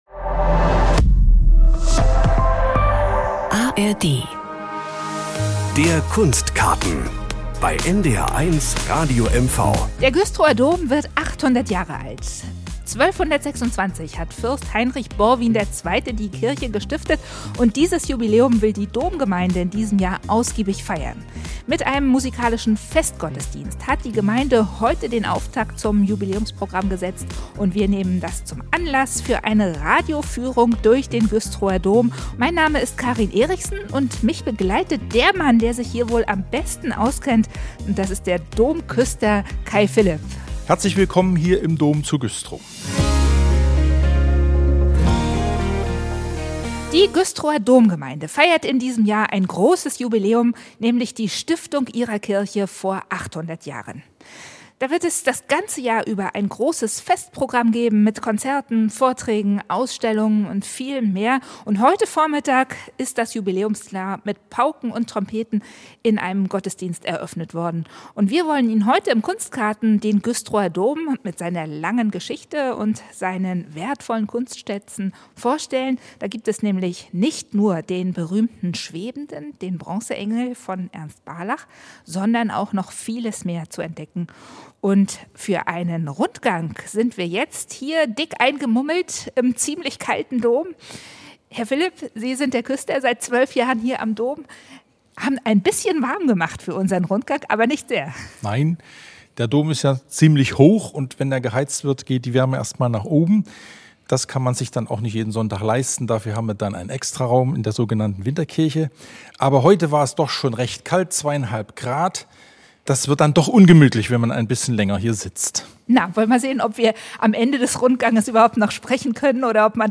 Wie klingt die Orgel und was unterscheidett dieses Instrument von anderen? Und wie soll das Jubiläum gefeiert werden?